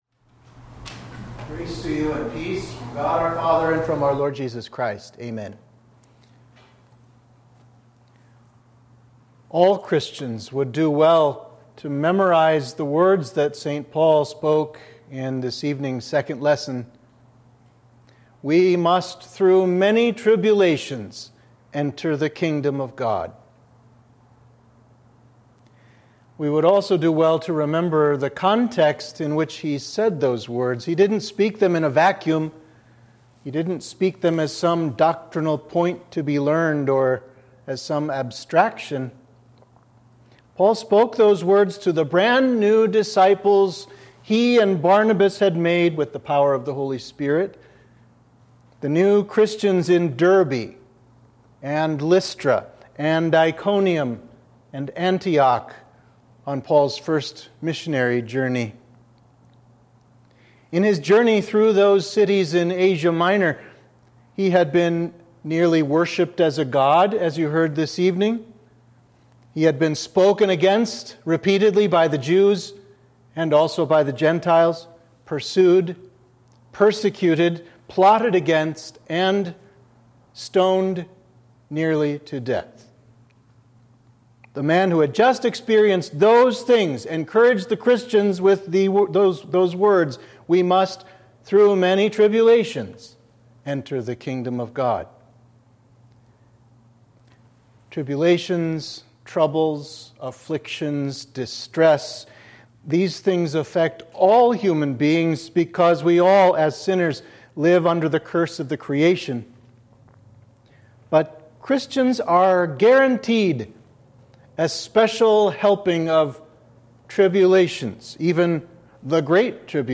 Sermon (audio)
Sermon for Midweek of Trinity 7